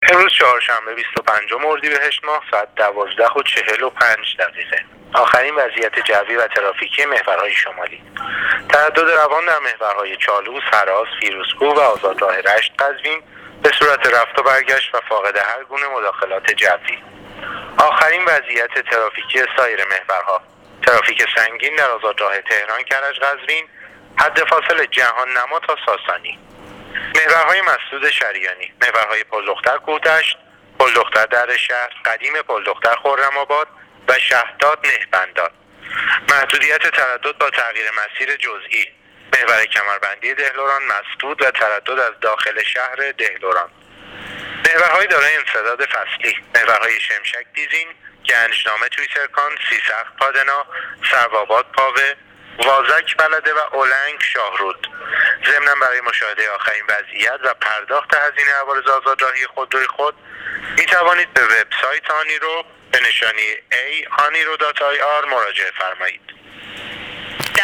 گزارش رادیو اینترنتی وزارت راه و شهرسازی از آخرین وضعیت‌ ترافیکی راه‌های کشور تا ساعت ۱۲:۴۵ بیست و پنج اردیبهشت/تردد روان در تمامی محورهای شمالی کشور/ترافیک سنگین در آزادراه تهران - کرج - قزوین